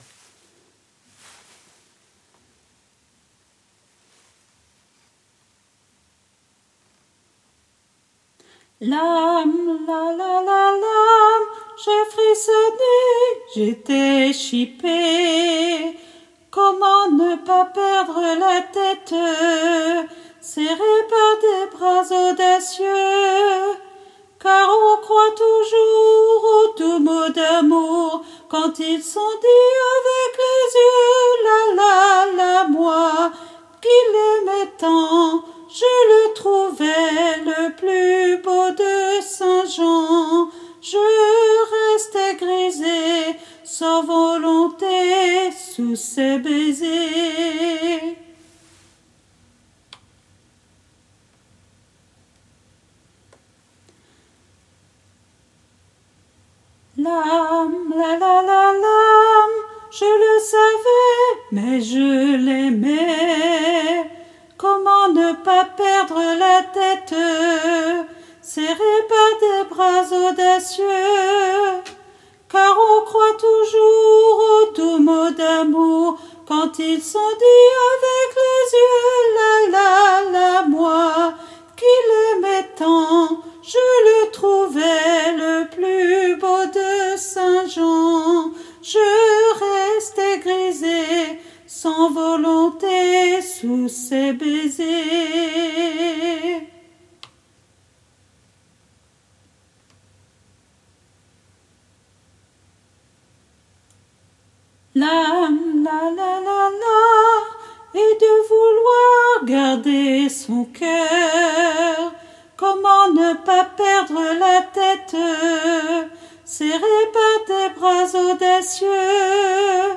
MP3 versions chantées
Hommes